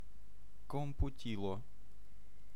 Ääntäminen
UK : IPA : /kəm.ˈpjuː.tə/ US : IPA : [kəm.ˈpjuː.ɾɚ]